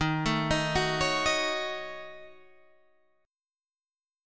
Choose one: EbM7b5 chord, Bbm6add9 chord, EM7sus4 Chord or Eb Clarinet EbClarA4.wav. EbM7b5 chord